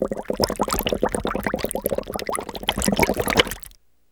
Index of /90_sSampleCDs/E-MU Producer Series Vol. 3 – Hollywood Sound Effects/Water/Bubbling&Streams